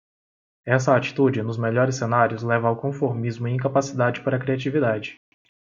/kɾi.a.t͡ʃi.viˈda.d͡ʒi/